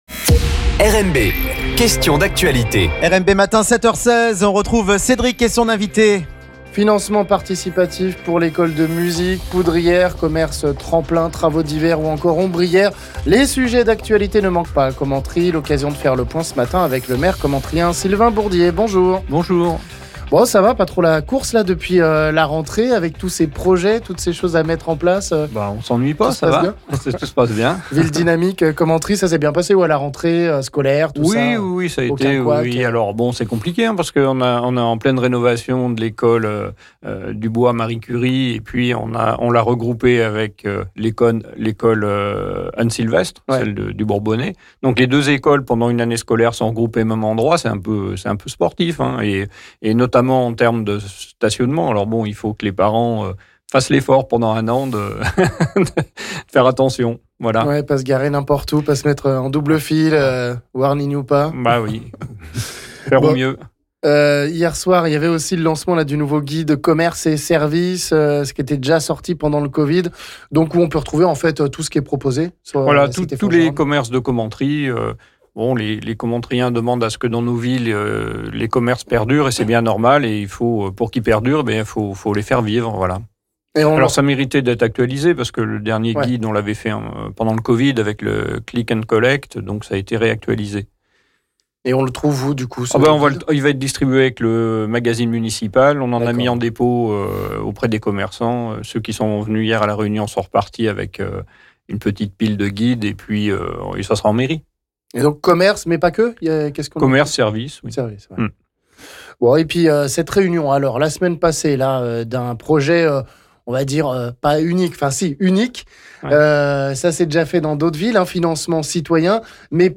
Présentation de cette initiative unique de la ville de Commentry, ce financement participatif et citoyen pour l'école de musique. Avec le maire commentryen Sylvain Bourdier on parle aussi des travaux dans les écoles et rues ou encore d'un commerce tremplin pour tenter d'attirer un commerçant...